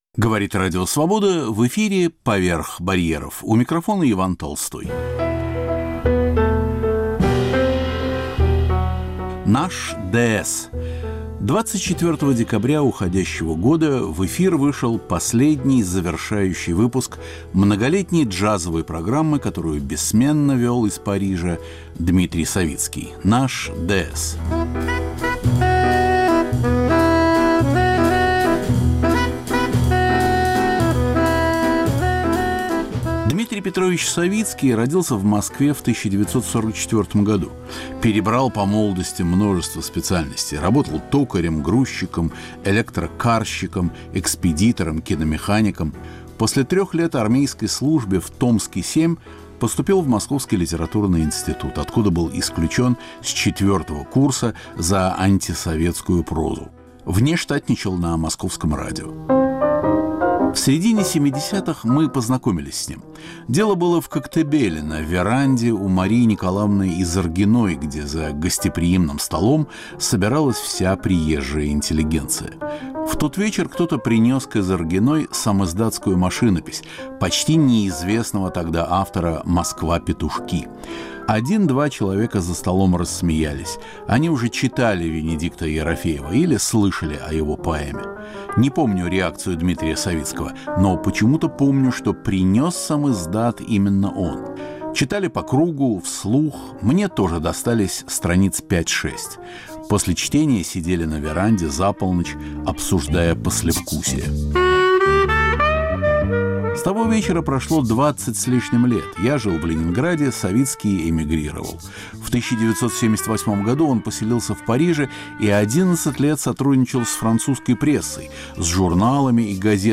Парижская панорама на фоне джазовых мелодий.